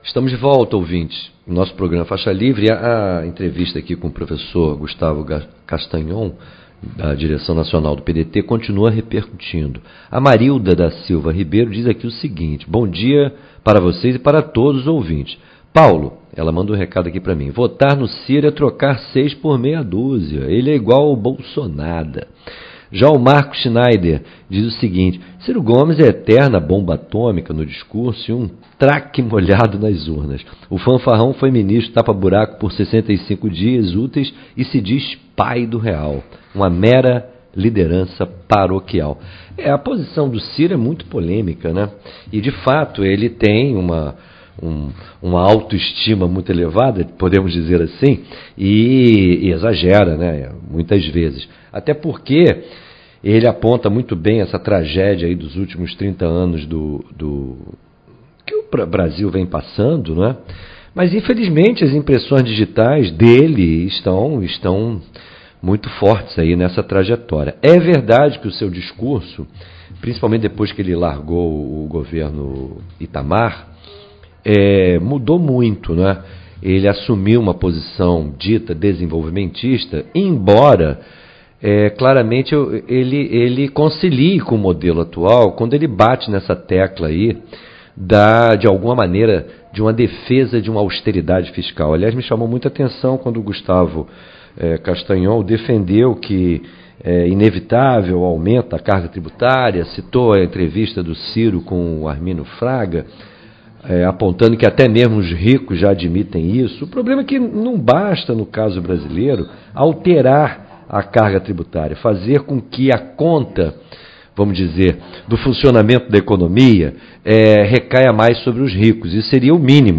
Entrevista no programa “Faixa Livre”: questão ambiental não é moda, é sobrevivência da espécie